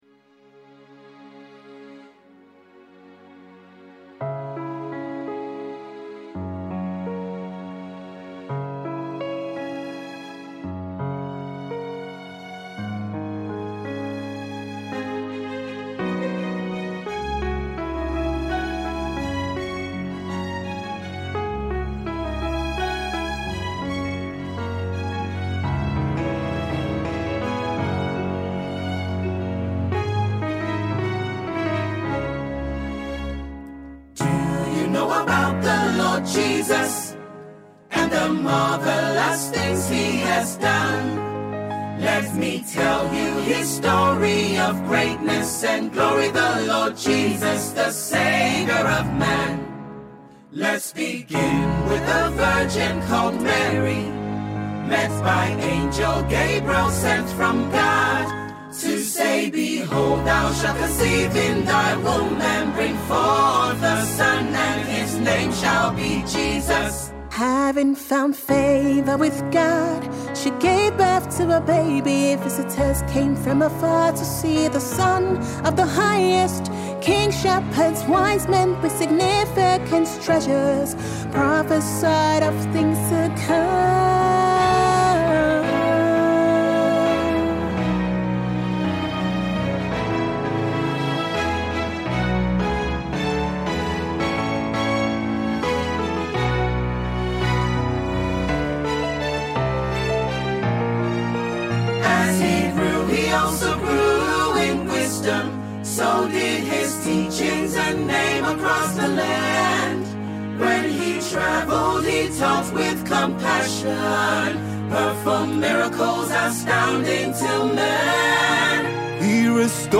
MORE CAROL SONGS